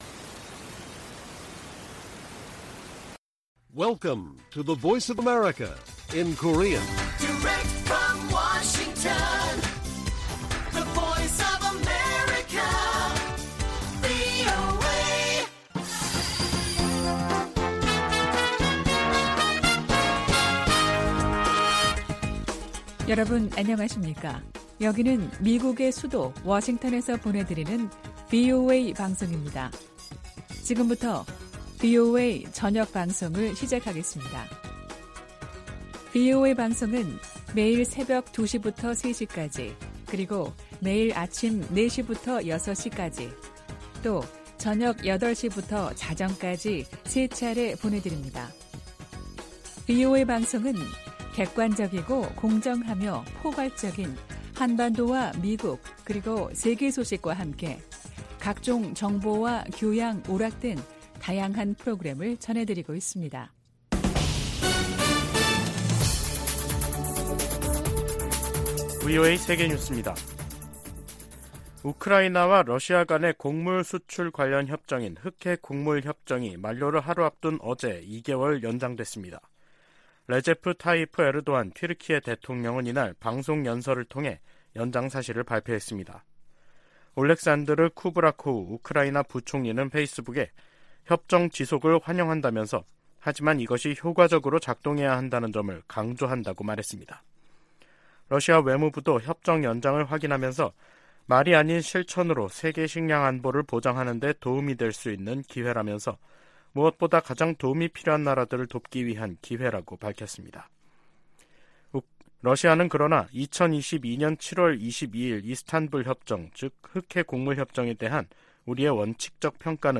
VOA 한국어 간판 뉴스 프로그램 '뉴스 투데이', 2023년 5월 18일 1부 방송입니다. 일본 히로시마에서 열리는 주요 7개국(G7) 정상회의 기간에 미한일 정상회담을 추진 중이라고 미국 백악관 고위 당국자가 밝혔습니다. 미 국무부 북한인권특사 지명자는 유엔 안보리의 북한 인권 공개회의를 재개하고 인권 유린에 대한 책임을 묻겠다고 밝혔습니다. 윤석열 한국 대통령이 주요국 정상들과 잇따라 회담을 갖는 '외교 슈퍼위크'가 시작됐습니다.